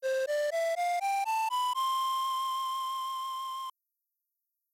Infine, per la maggior parte degli strumenti si programmava un "loop" che ripeteva una parte del campione all'infinito: un procedimento fondamentale per permettergli di suonare note più lunghe senza dover allungare il campione stesso e occupare altro spazio.
Il sample loopato e inserito nella rom può essere utilizzato per creare delle melodie.
flauto-snes-1.wav